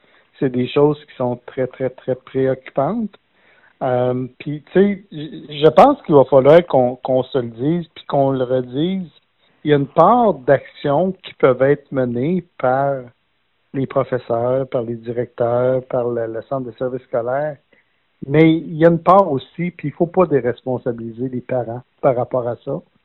En entrevue, le député de Nicolet-Bécancour a mentionné qu’avant la conférence de presse du 14 novembre dernier, personne ne lui avait parlé de cette problématique.